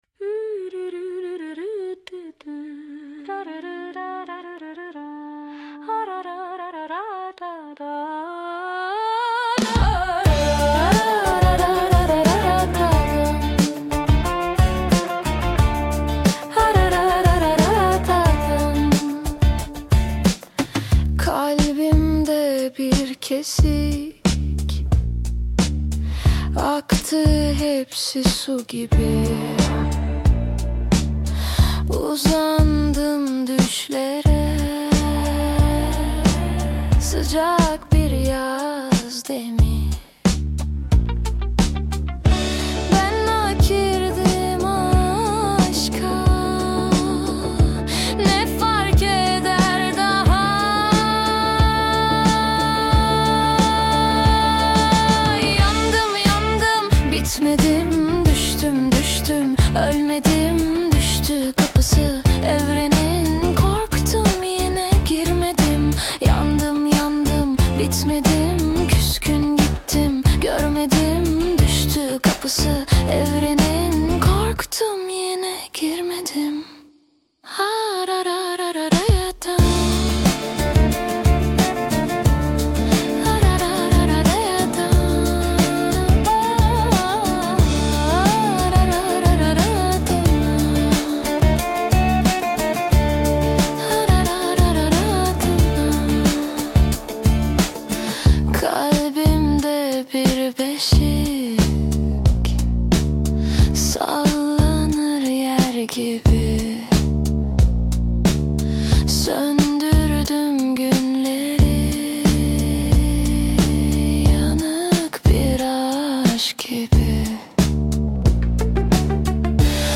Tür : Funk, Indie-Pop, Pop